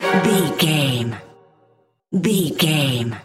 Aeolian/Minor
Fast
scary
tension
ominous
dark
eerie